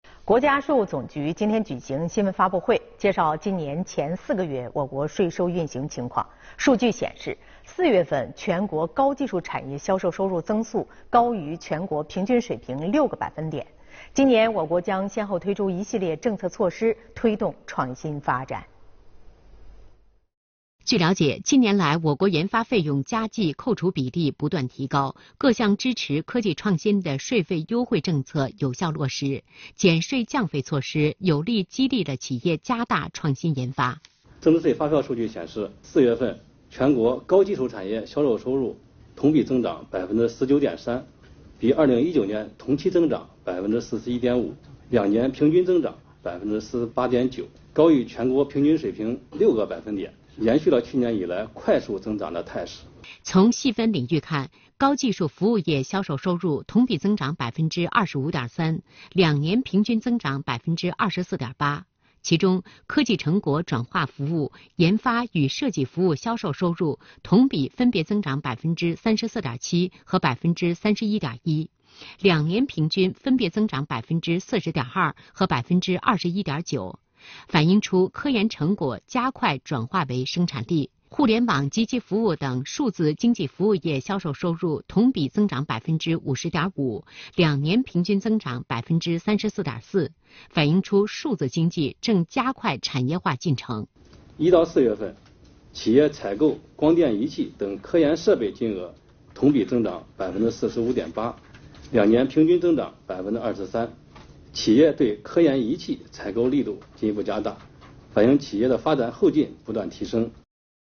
视频来源：央视《新闻直播间》